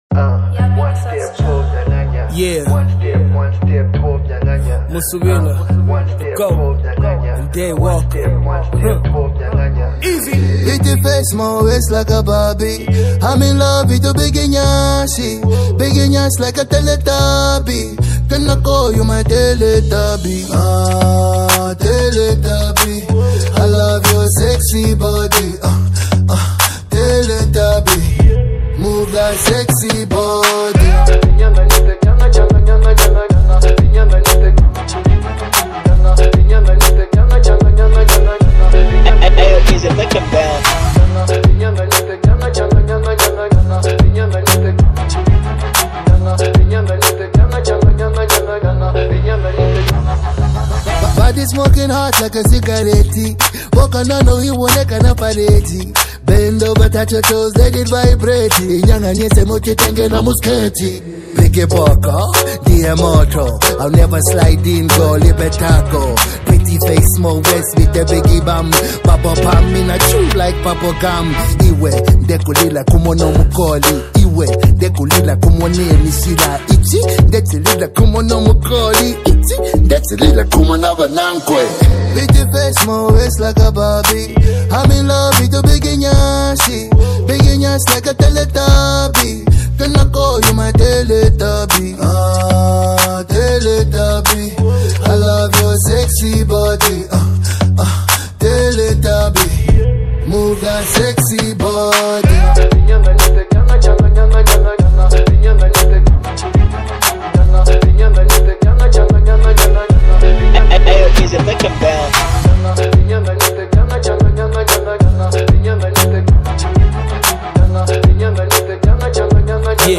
combines a playful beat with entertaining lyrics
The track’s upbeat and whimsical nature makes it a standout.